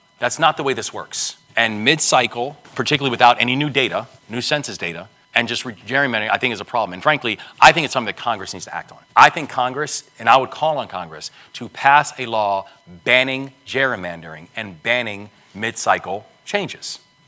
One of the subjects that came up a t a recent National Press Club appearance by Maryland Governor Wes Moore was the hot topic of gerrymandering.